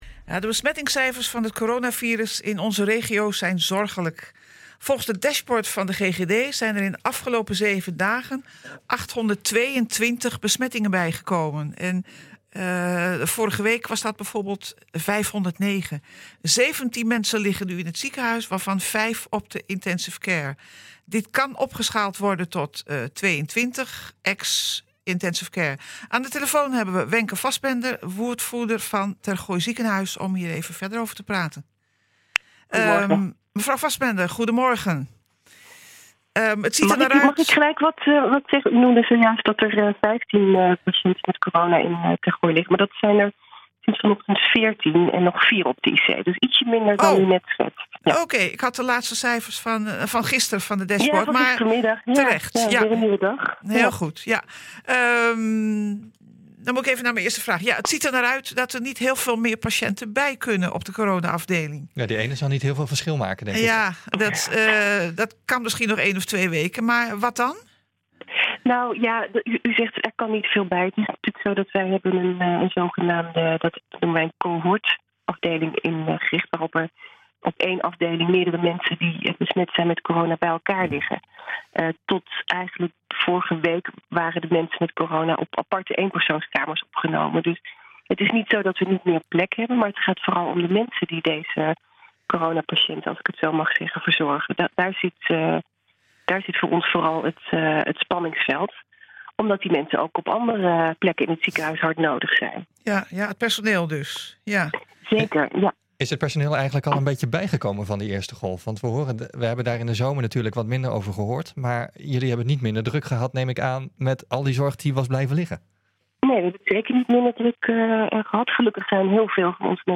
Aan de telefoon